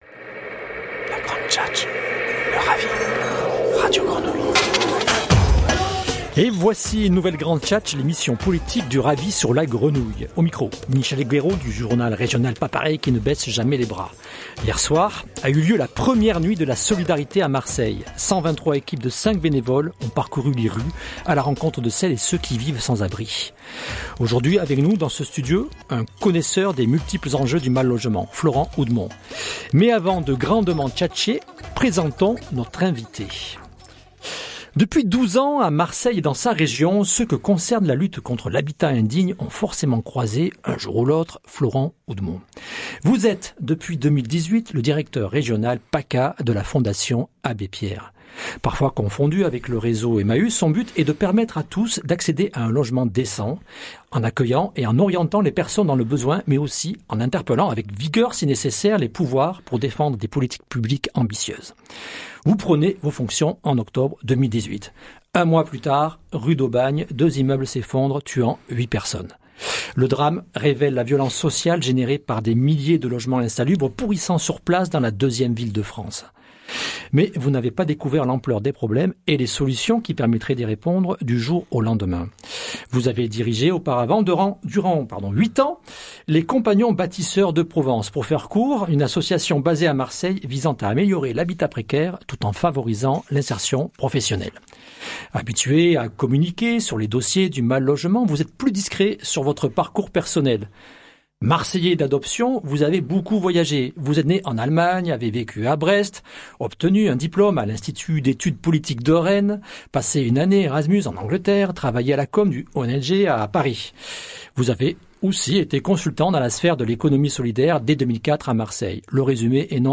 Entretien radio